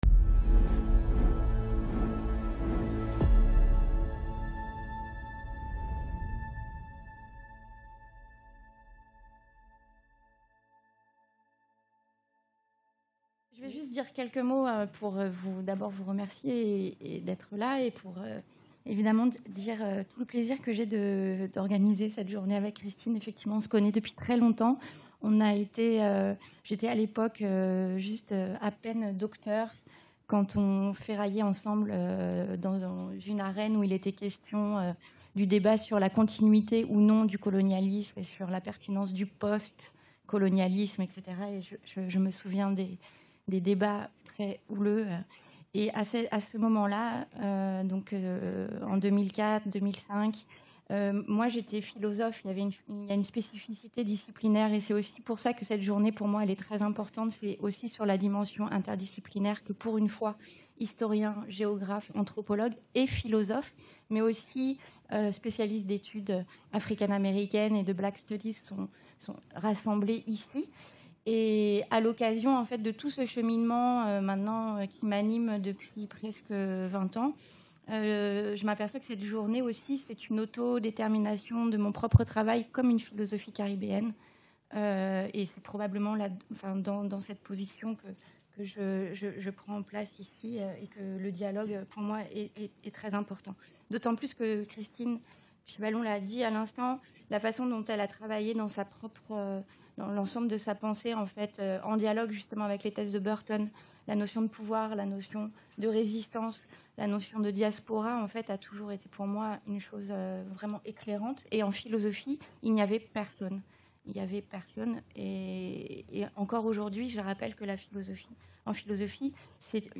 Journée d’étude Mondes Caraïbes et Transatlantiques en Mouvement - Mercredi 3 octobre Focus sur les révoltes anticoloniales (Martinique – Guadeloupe – Madagascar) La violence est intrinsèque à la colonisation qui est l’une des formes de domination les plus coercitives allant bien au-delà de la fabrique du consentement, même si cette dernière intervient dans les processus de stabilisation de la relation d’assujettissement. Cette violence s’exerce sur les corps de manière brutale, dans l’ensemble des processus de disciplinarisation qu’exige le maintien d’un ordre essentiellement conquérant et hiérarchique, confinant à la déshumanisation et à l’animalisation du colonisé.